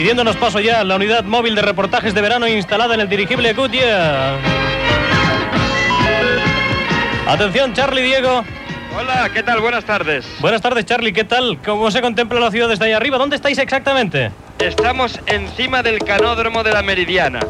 Connexió amb el dirigible Goodyear sobrevolant Barcelona.